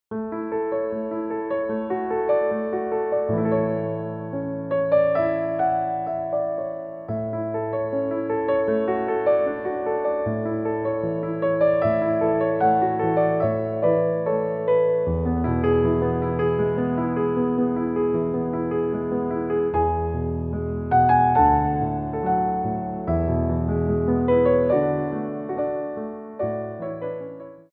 Piano Arrangements
35 Tracks for Ballet Class.
Warm Up
4/4 (16x8)